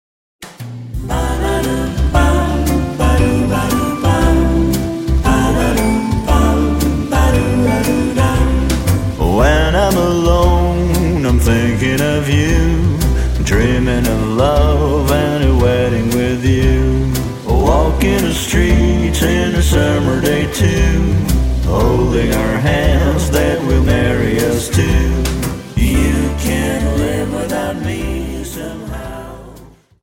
Dance: Slowfox Song